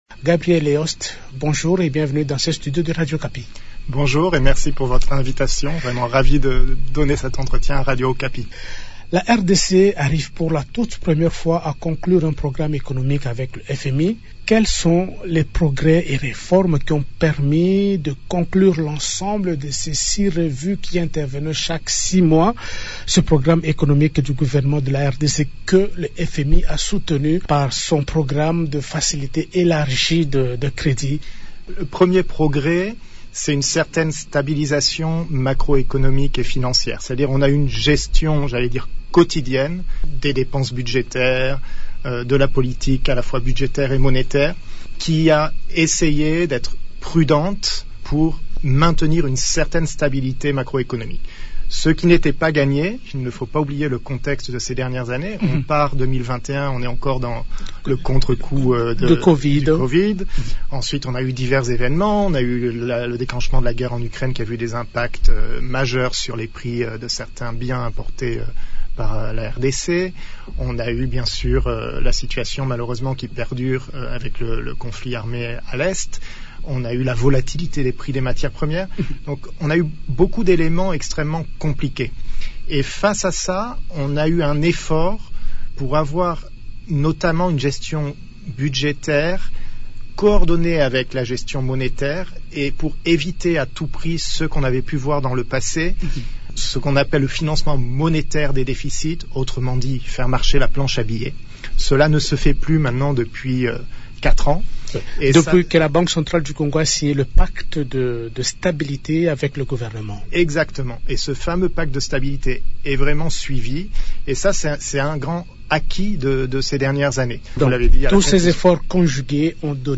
Mais avant de partir, il s'est confié à Radio Okapi dans une interview exclusive pour parler du programme économique de la RDC soutenu par la facilité élargie de crédit du FMI, programme qui a marqué son passage de trois ans en RDC.